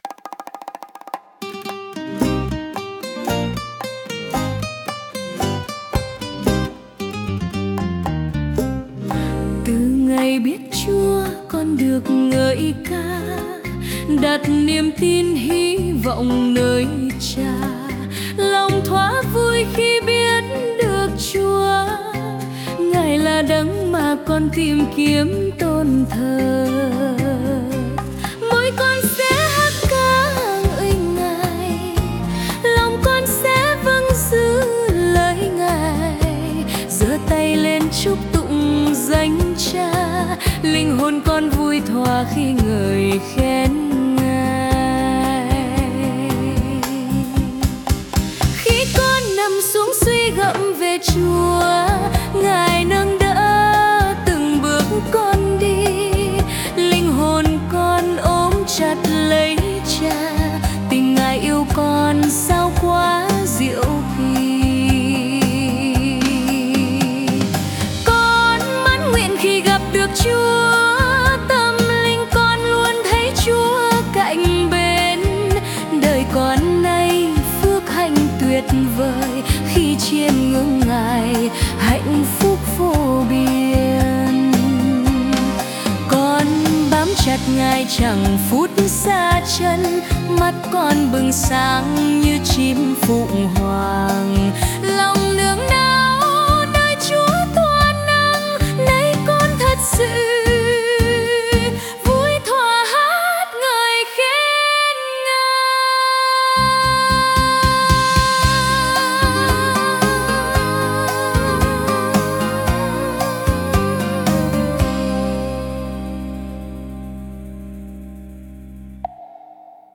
Nhạc AI